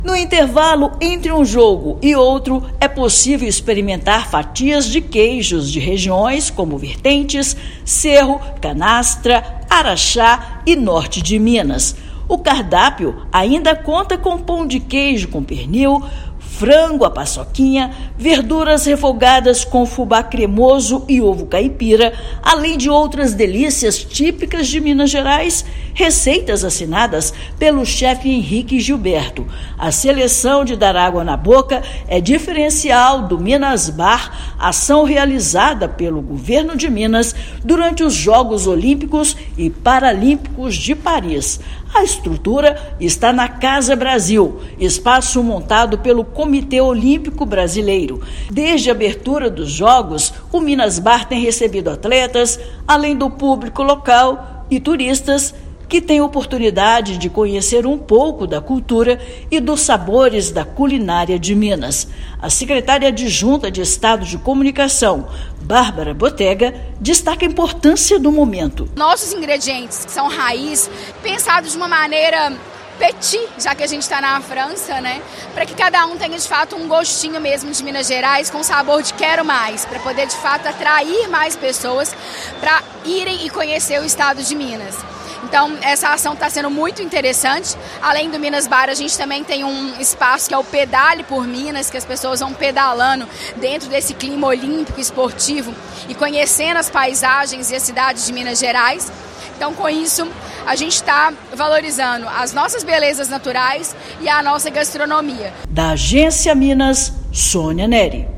Iniciativa Minas Bar, na Casa Brasil, é vitrine mundial para um dos principais atrativos turísticos e culturais: a cozinha. Ouça matéria de rádio.